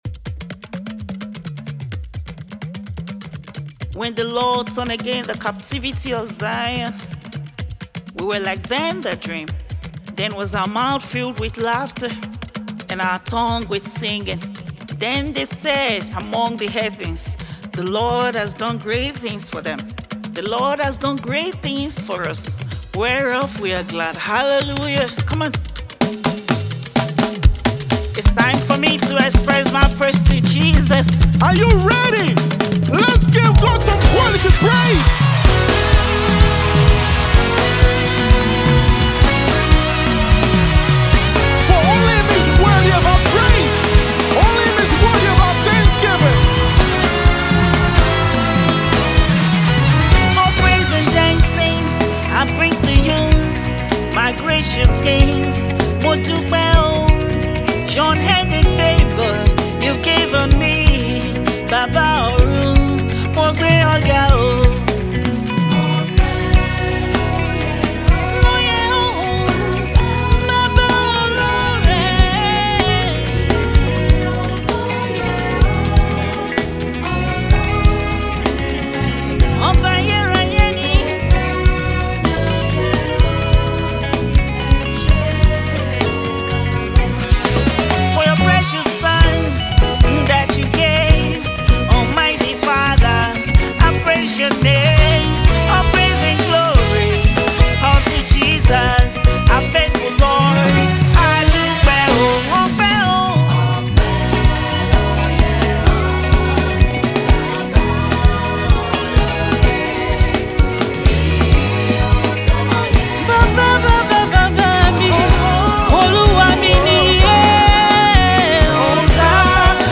Gospel Music 🎶